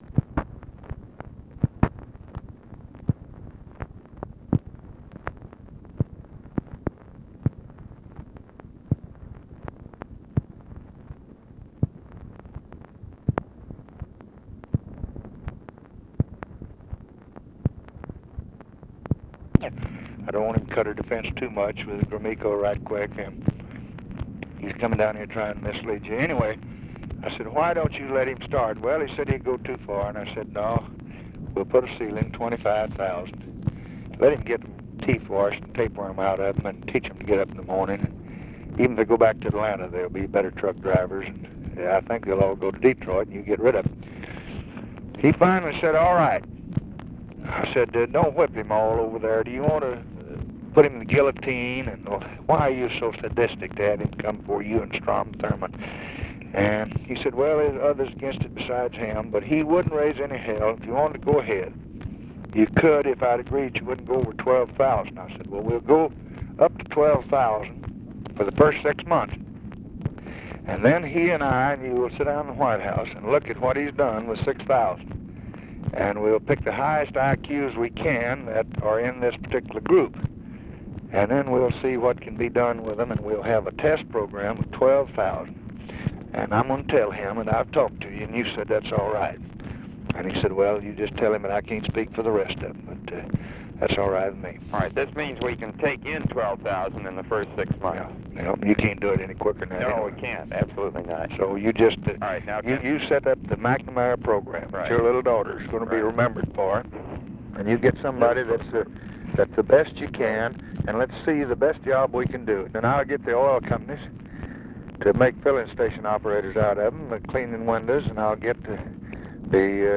Conversation with ROBERT MCNAMARA, December 9, 1964
Secret White House Tapes